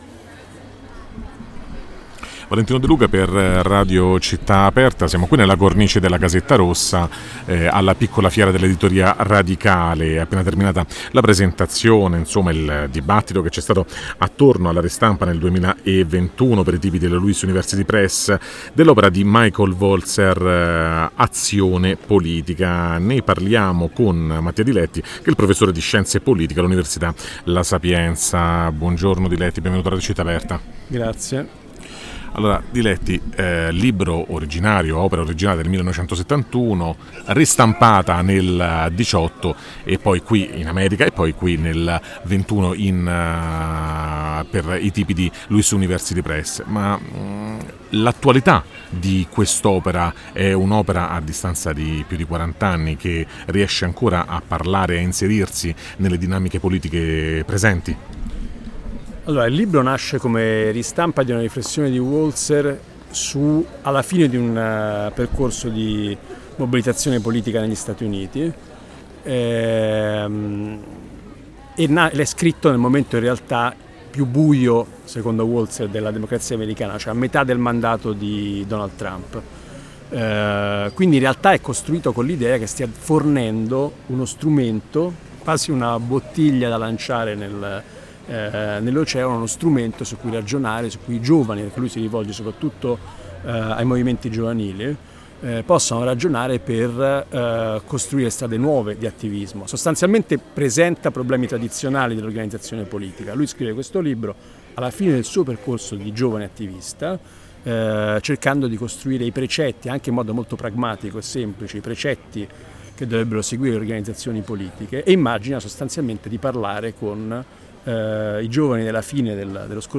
Il 28 – 29 e 30 settembre 2021, nella sede di Casetta Rossa al quartiere Garbatella di Roma, si è tenuta un’importante manifestazione dell’editoria indipendente e “radicale”.